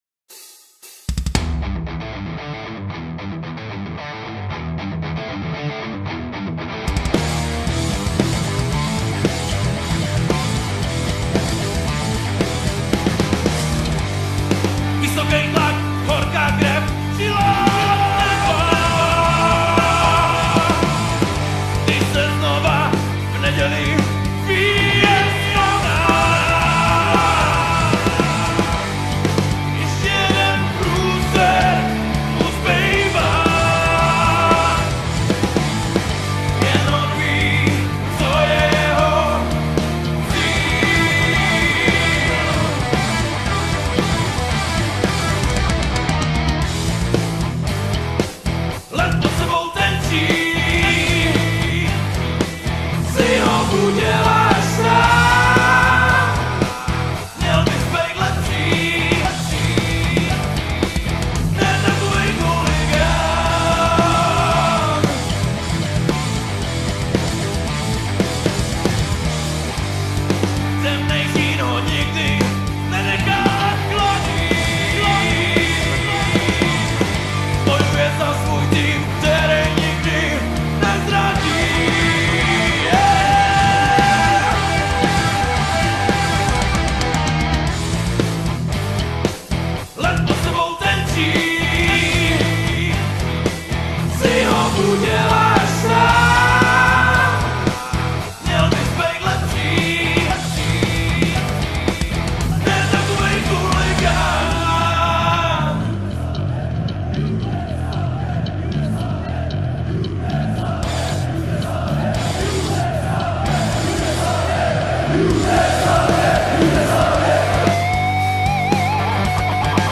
na stará kolena jsem si odběhl od jazzu zase k bigbítu